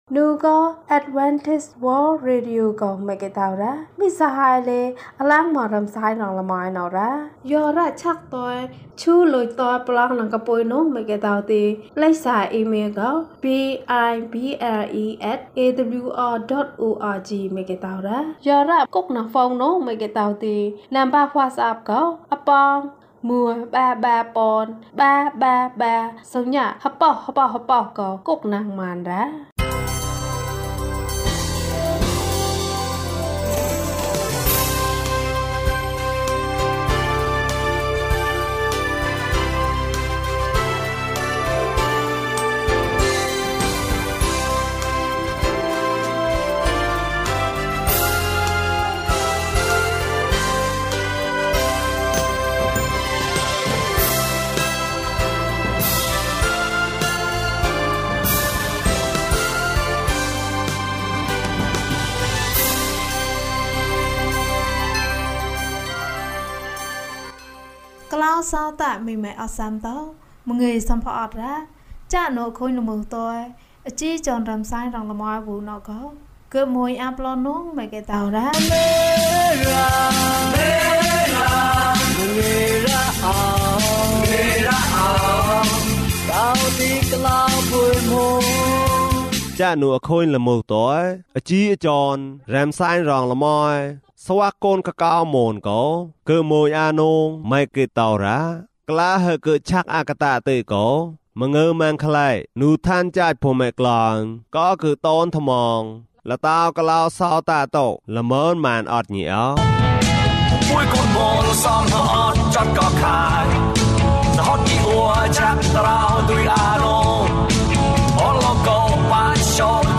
ခရစ်တော်ထံသို့ ခြေလှမ်း။၅5 ကျန်းမာခြင်းအကြောင်းအရာ။ ဓမ္မသီချင်း။ တရားဒေသနာ။